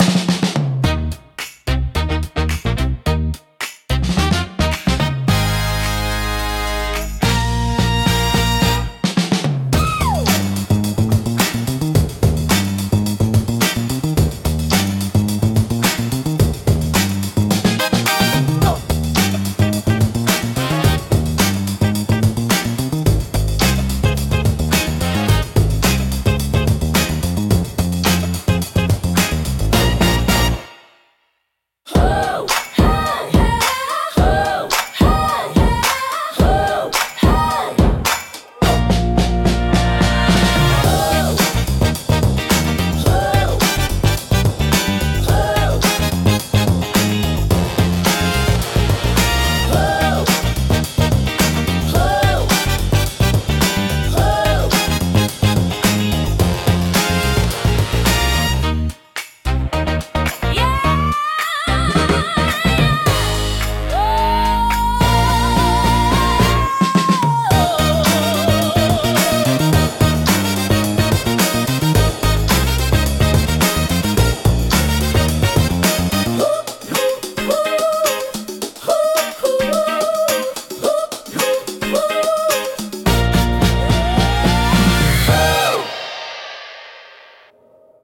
心に残るハーモニーと温かさが魅力のジャンルです。